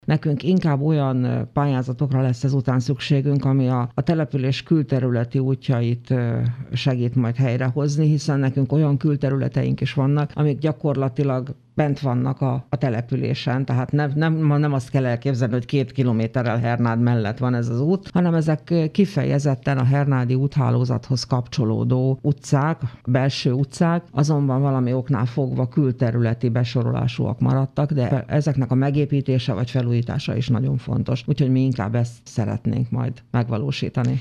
A település külterületi útjaihoz lenne leginkább szükség pályázatra Hernádon. A községhez több kilométernyi úthálózat tartozik, amelyek felújítása csak pályázati támogatásból lehetséges. Zsírosné Pallaga Mária polgármestert hallják.